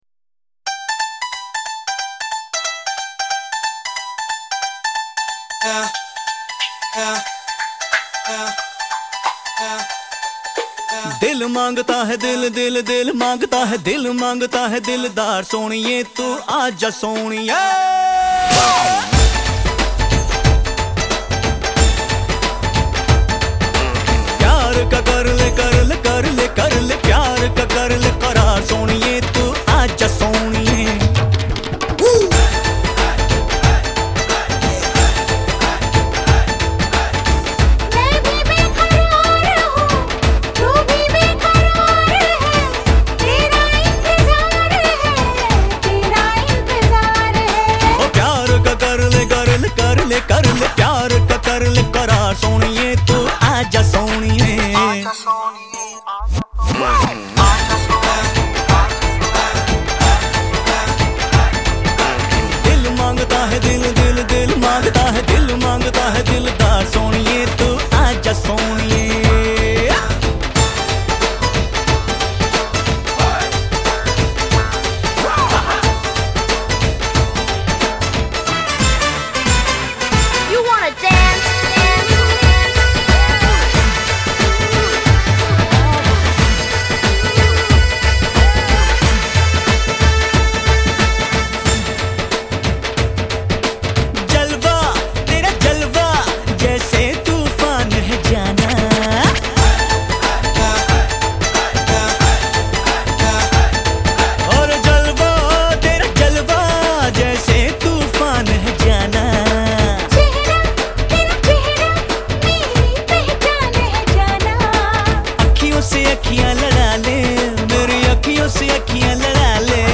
Home » Bollywood Mp3 Songs » Bollywood Movies